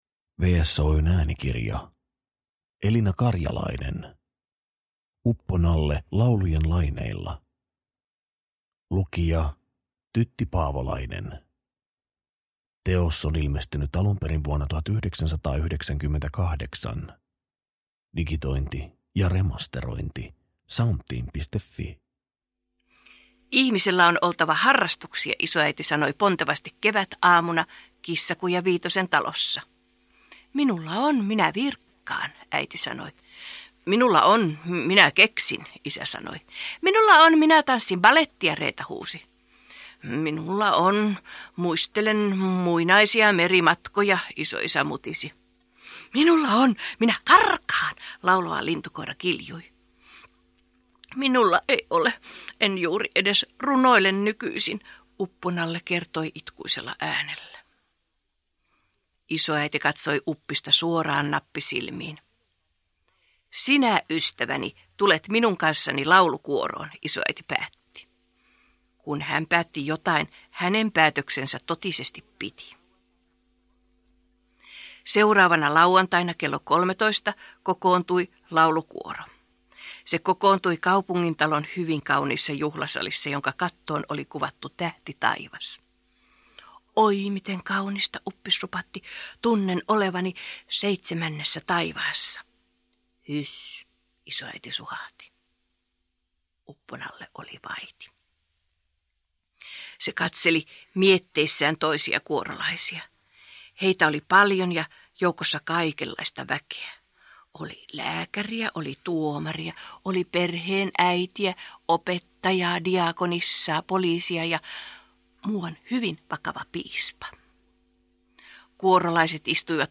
Uppo-Nalle laulujen laineilla – Ljudbok – Laddas ner